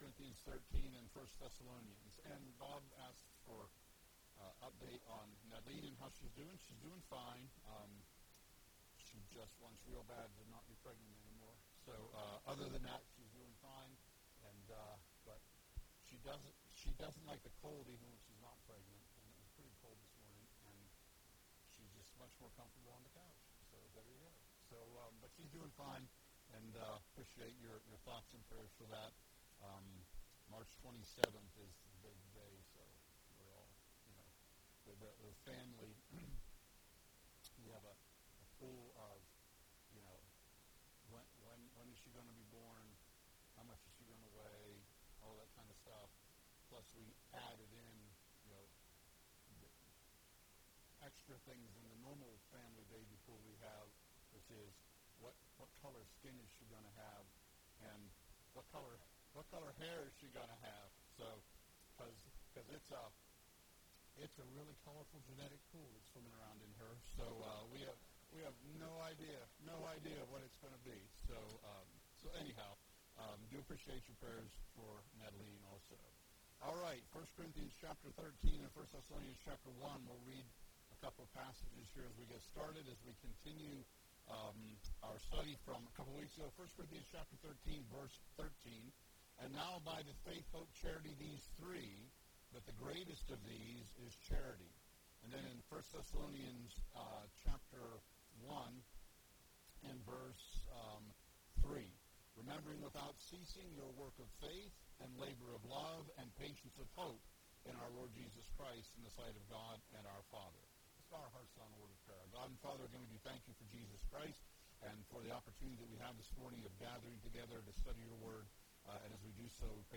We are currently meeting in the Bellwood library until the renovations are complete.)